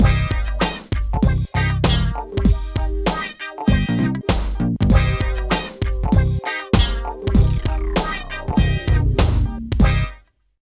74 LOOP   -L.wav